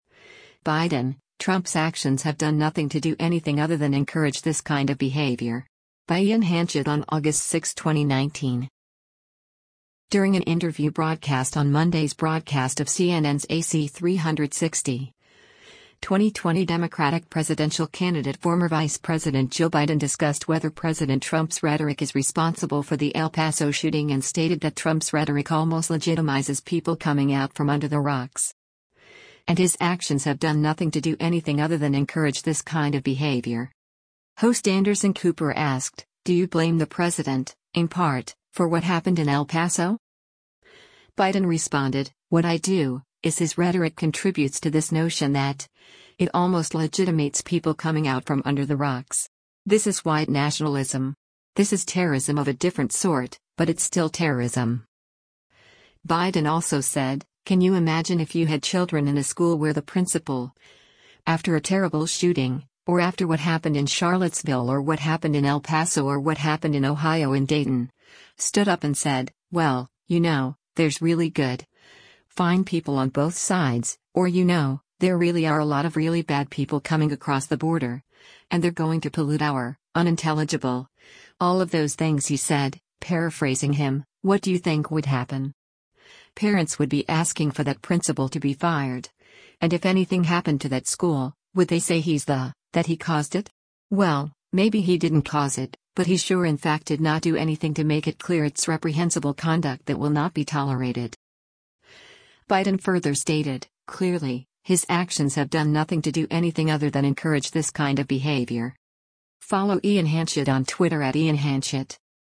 During an interview broadcast on Monday’s broadcast of CNN’s “AC360,” 2020 Democratic presidential candidate former Vice President Joe Biden discussed whether President Trump’s rhetoric is responsible for the El Paso shooting and stated that Trump’s rhetoric almost legitimizes “people coming out from under the rocks.”
Host Anderson Cooper asked, “Do you blame the president, in part, for what happened in El Paso?”